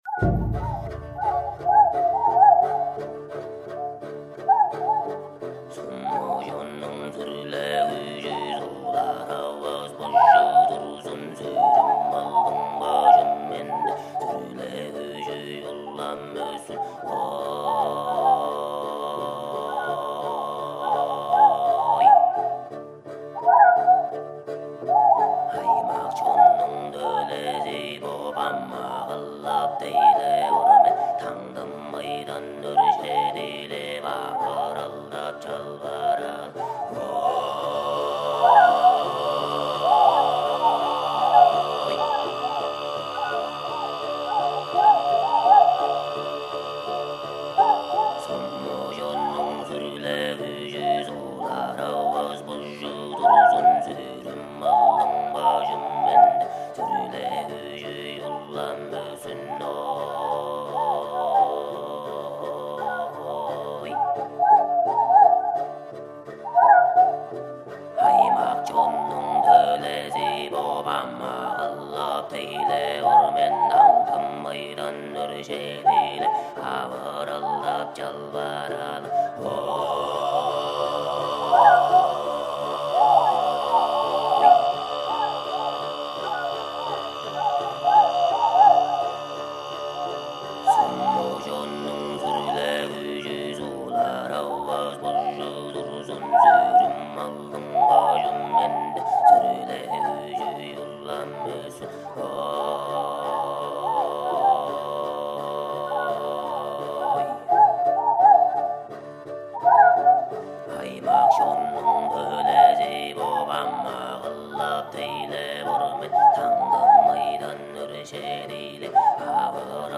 a prayer
the Tuvanese throat-singing group, with swan song mixed in